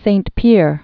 (sānt pîr, pē-âr, săɴ pyĕr; mĭkə-lŏn, mē-klôɴ)